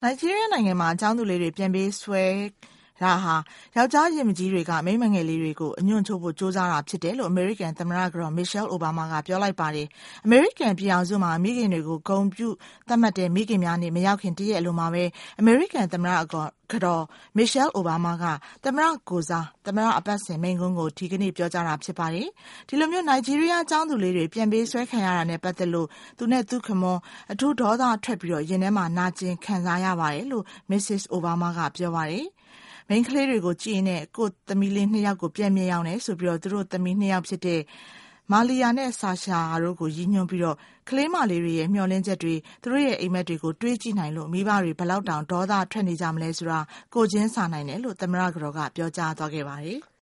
Obama address